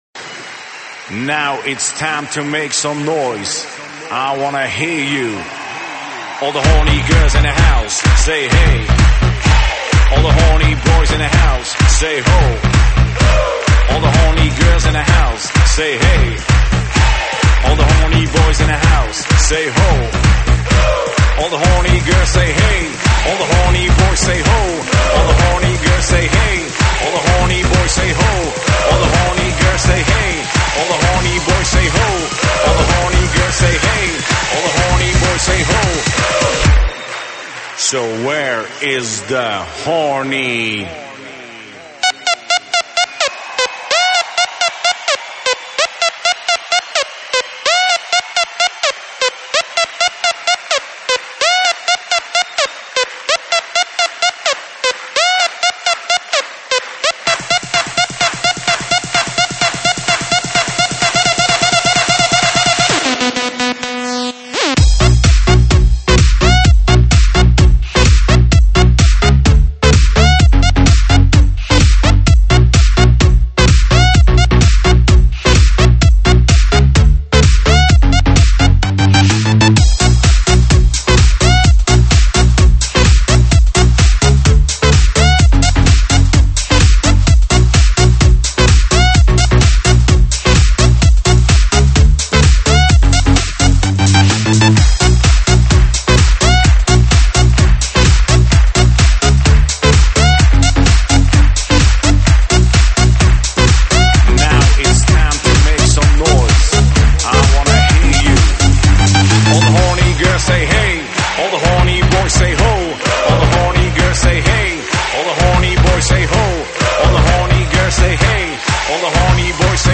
收录于(现场串烧)